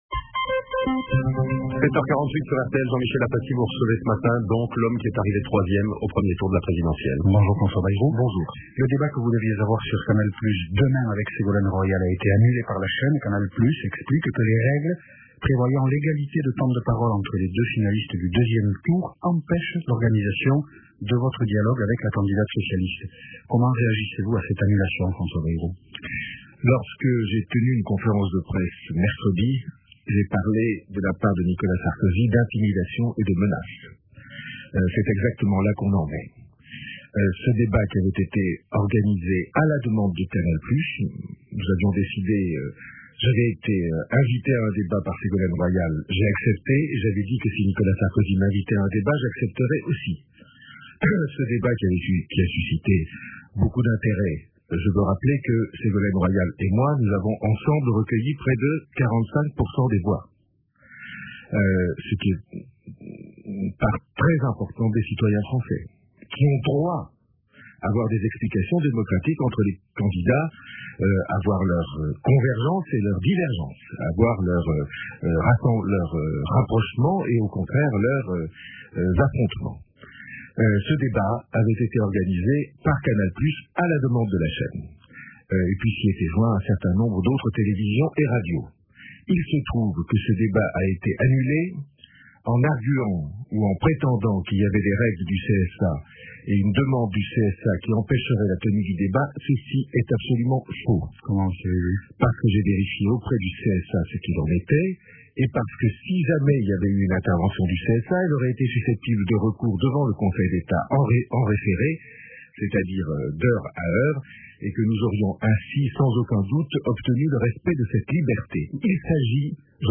Excusez-moi du mauvais son mais le serveur n'accepte que les fichiers de moins de 1 Mo :
Transférez cet extrait de l'intervention de François Bayrou ce matin sur RTL au plus grand nombre de vos relations.
Invité : François BAYROU, président de l’UDF.